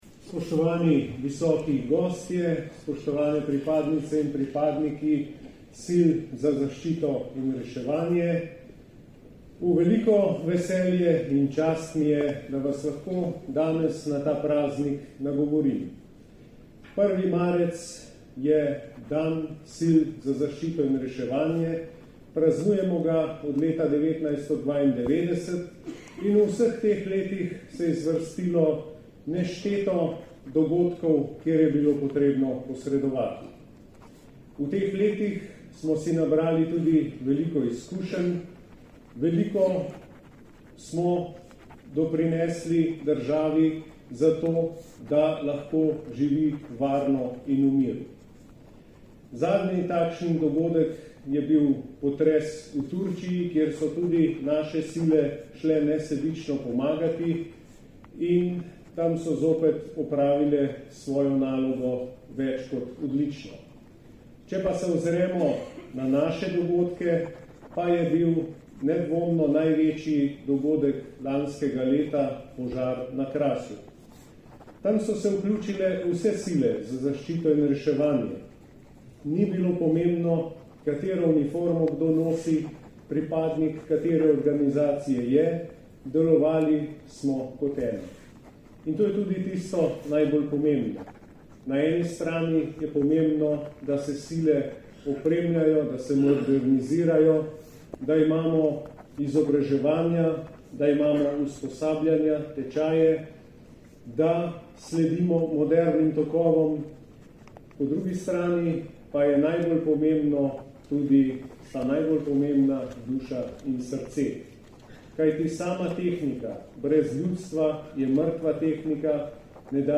Nagovor ministra za obrambo Marjana Šarca
Ob dnevu civilne zaščite je danes na Brdu pri Kranju potekala tradicionalna slovesnost, na kateri so podelili nagrade in priznanja za minulo leto.